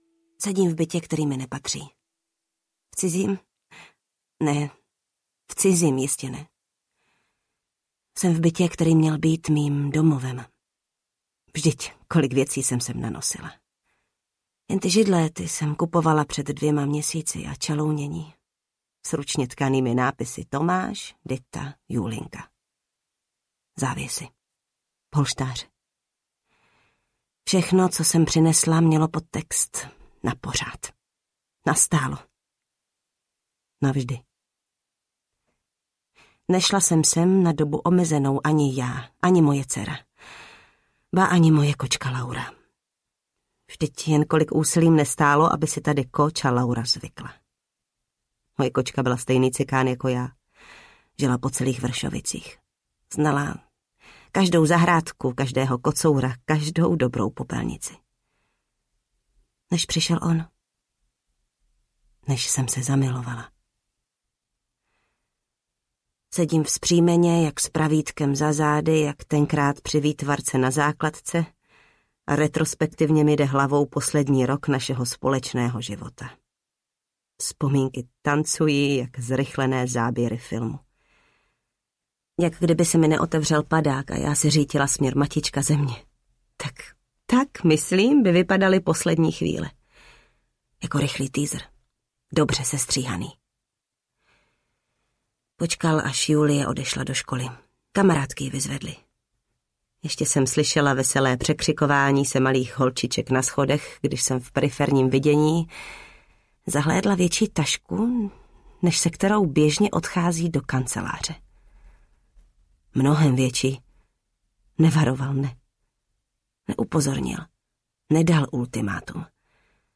Laskonky audiokniha
Ukázka z knihy
• InterpretJana Stryková